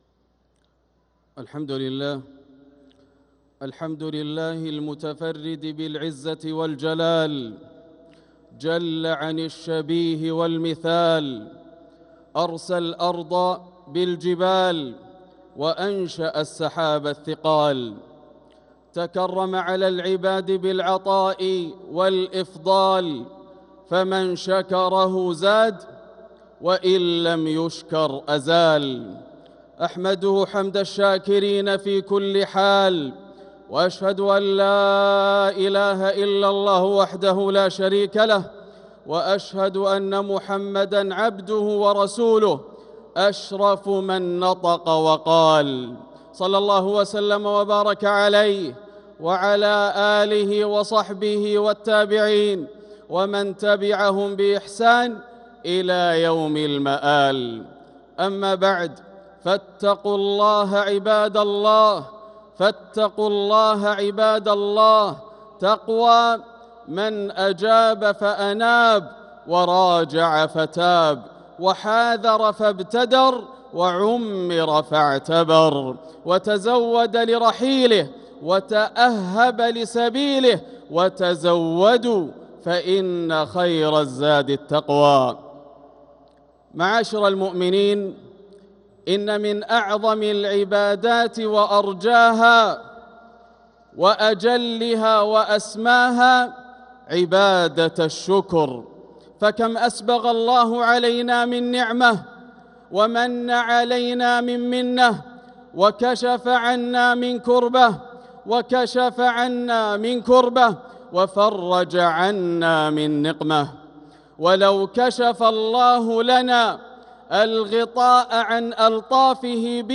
خطبة الجمعة 1-8-1446هـ بعنوان الشكر > خطب الشيخ ياسر الدوسري من الحرم المكي > المزيد - تلاوات ياسر الدوسري